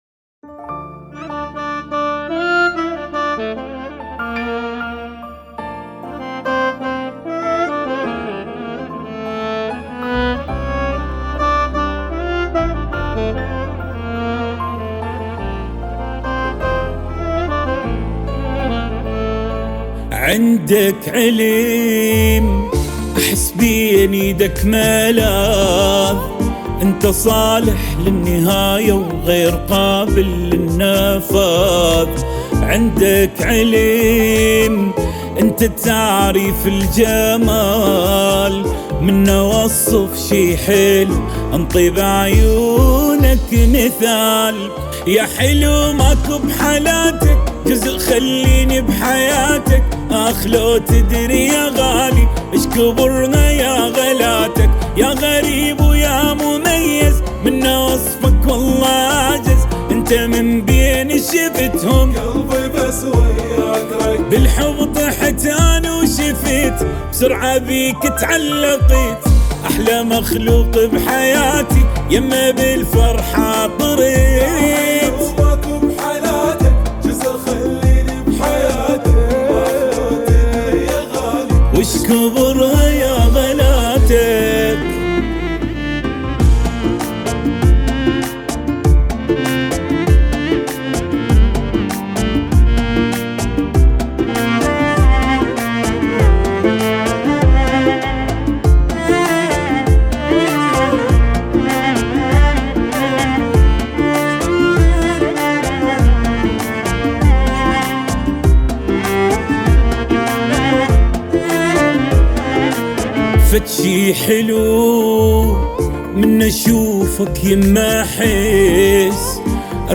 اغاني عراقية